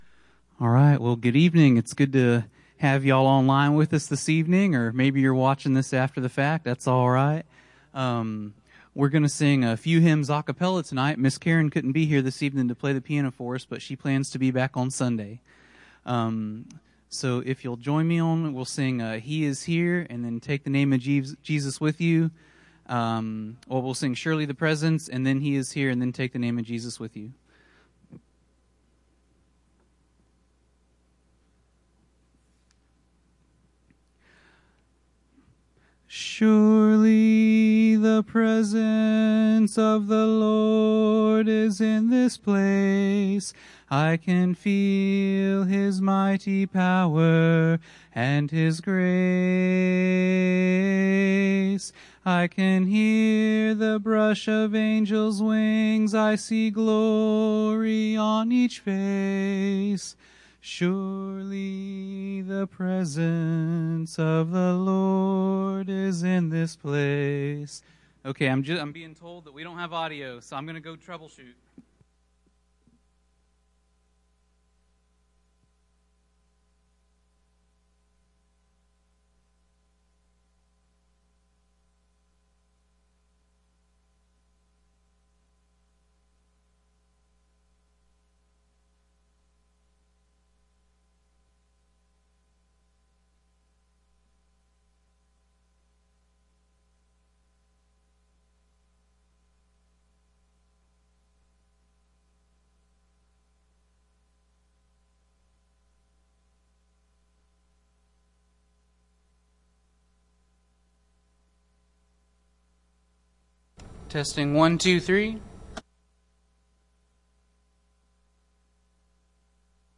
Bible Text: Exodus 7:14-25; 8:1-2 | Preacher